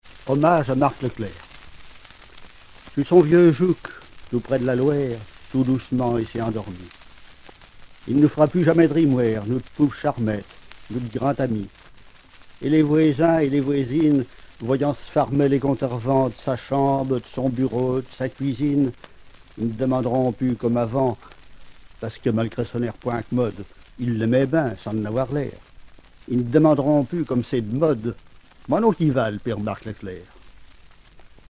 Langue Angevin
Genre poésie
Catégorie Récit